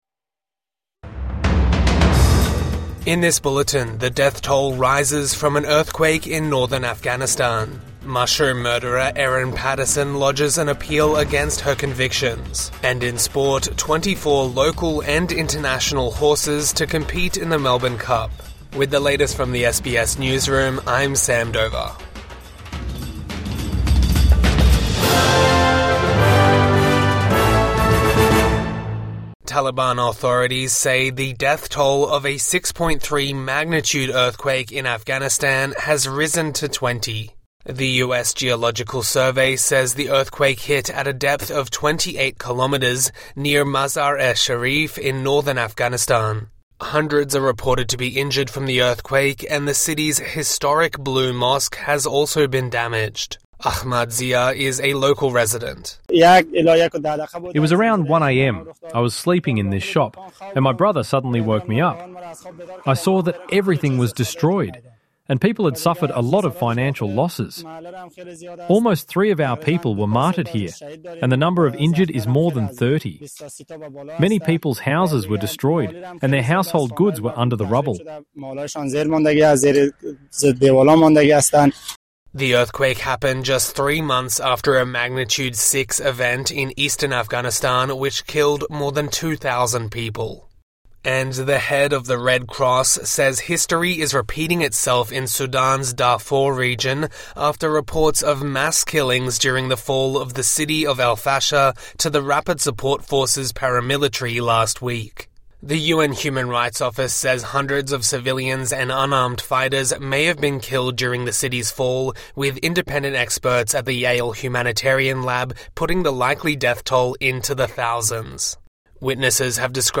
Mushroom murderer to appeal convictions | Morning News Bulletin 4 November 2025